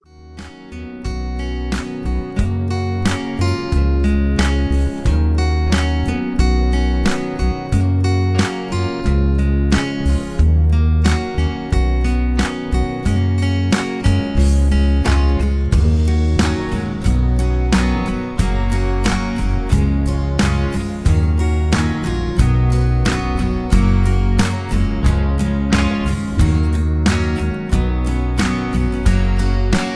(Key-Ebm) Karaoke MP3 Backing Tracks